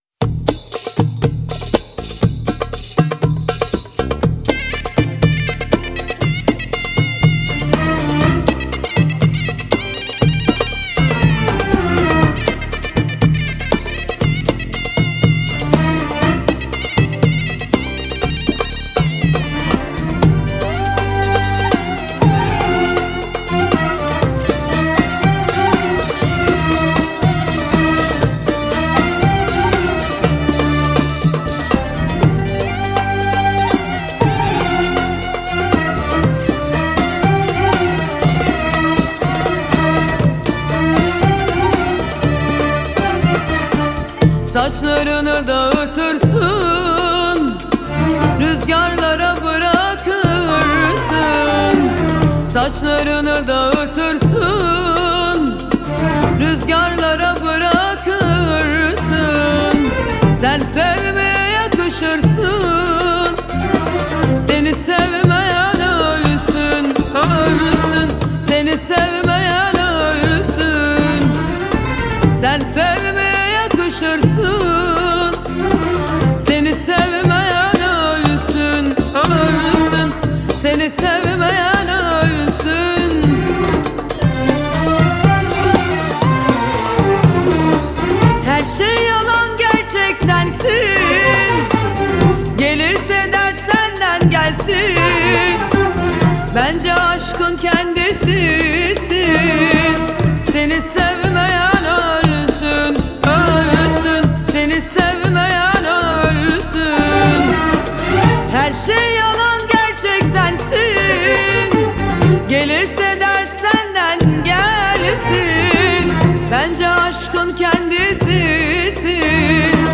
آهنگ ترکیه ای آهنگ غمگین ترکیه ای آهنگ هیت ترکیه ای
این ترانه زیبا در سال ۲۰۲۴ توسط این خواننده محبوب خوانده شده